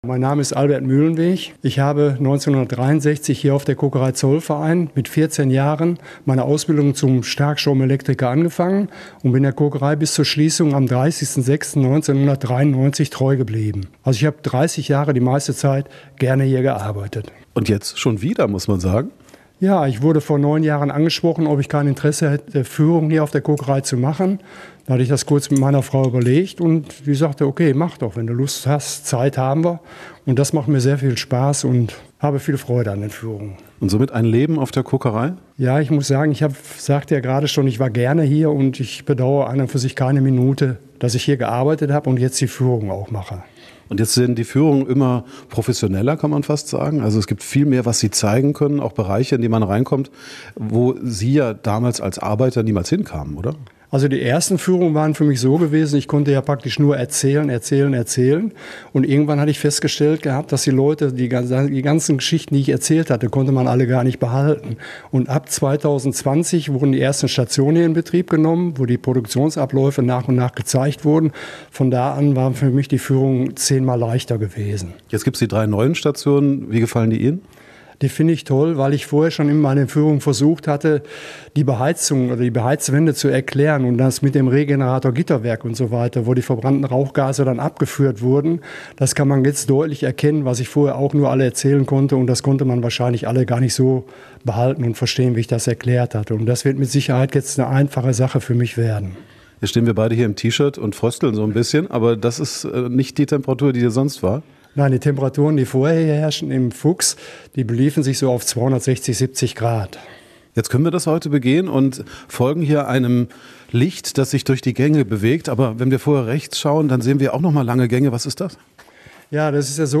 Exklusive Führung für Radio Essen